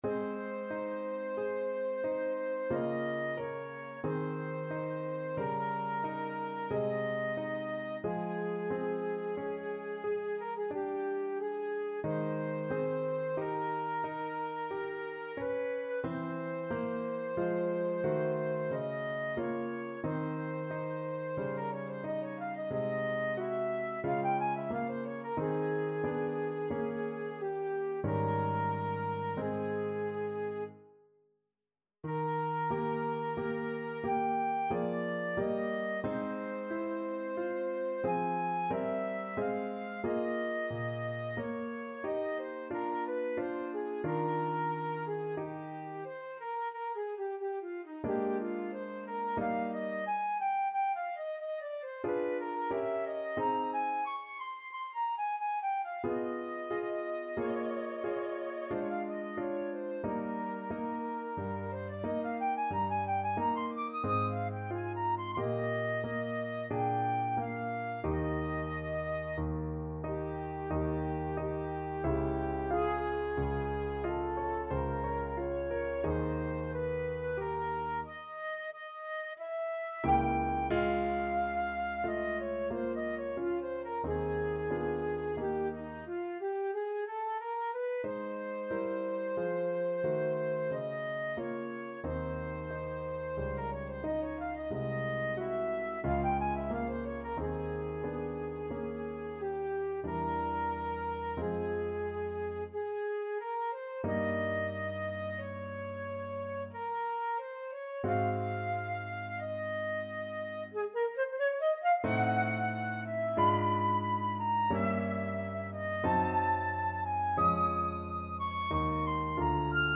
Classical Spohr, Louis Clarinet Concerto No.1, Op.26, Second Movement Flute version
Flute
Ab major (Sounding Pitch) (View more Ab major Music for Flute )
Adagio =45
Classical (View more Classical Flute Music)